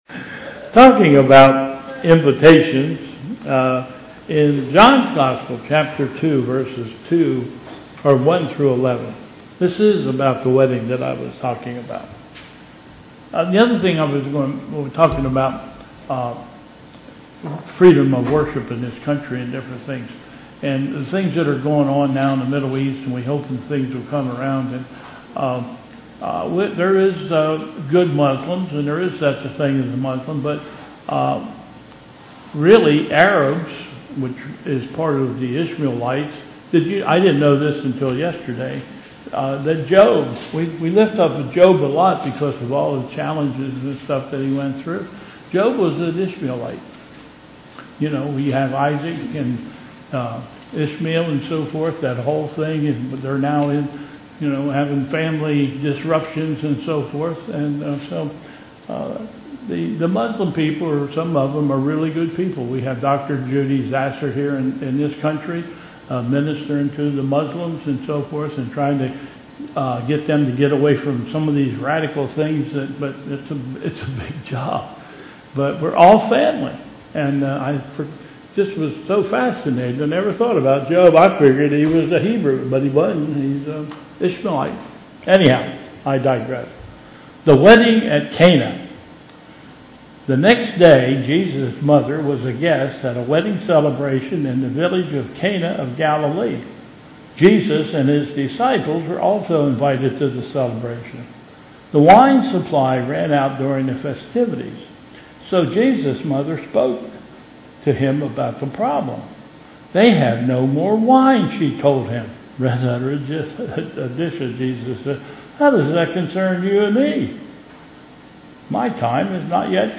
Bethel Church Service
Special Music